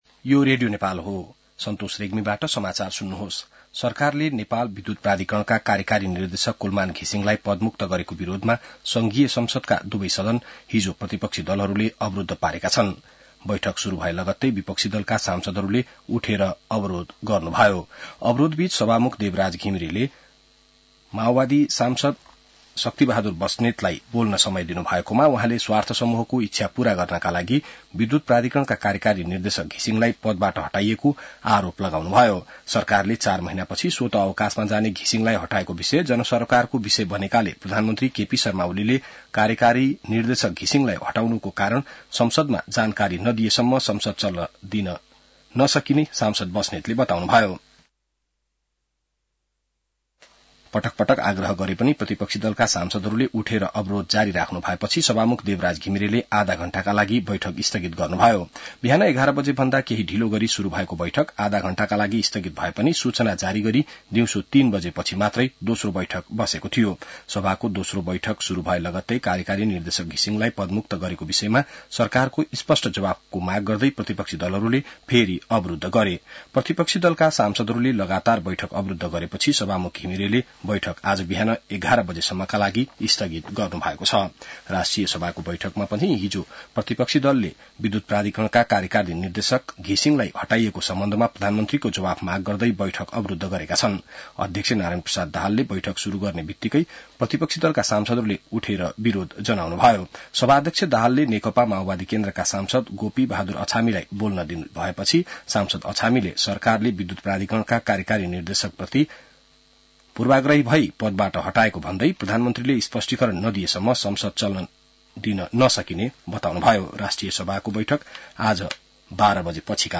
बिहान ६ बजेको नेपाली समाचार : १४ चैत , २०८१